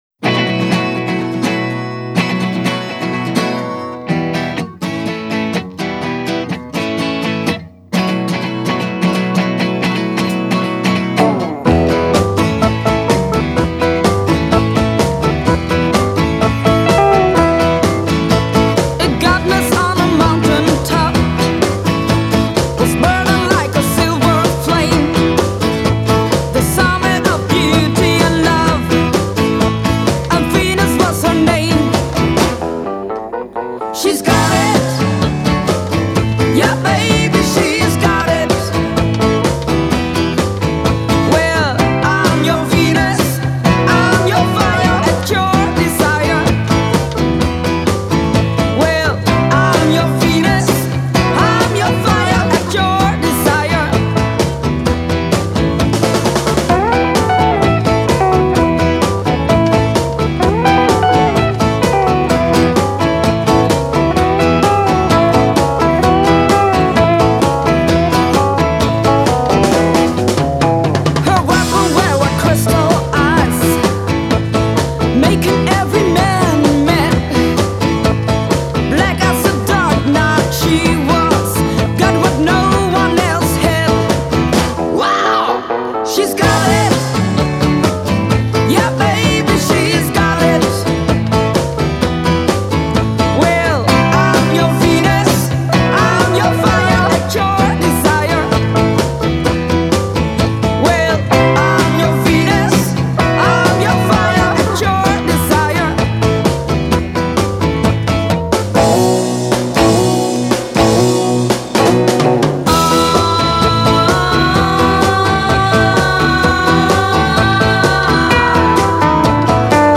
vocals, guitar
bass guitar
drums